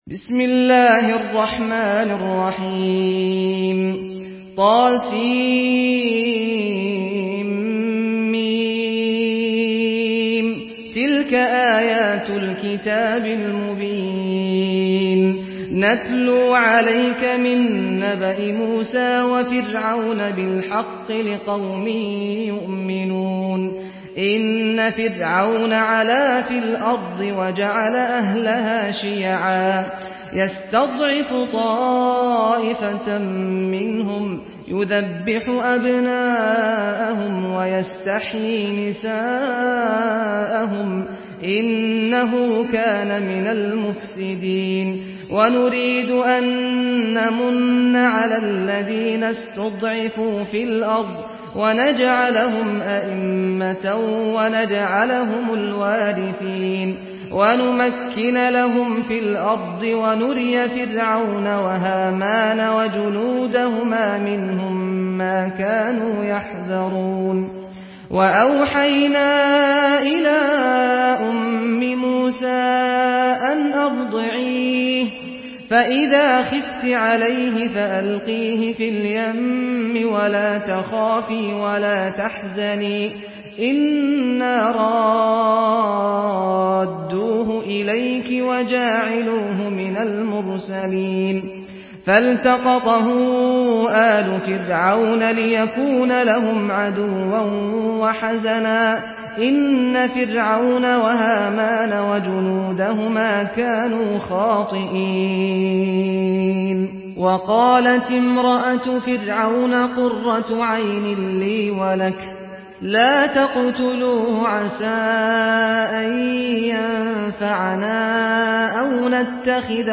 Surah القصص MP3 in the Voice of سعد الغامدي in حفص Narration
Listen and download the full recitation in MP3 format via direct and fast links in multiple qualities to your mobile phone.
مرتل حفص عن عاصم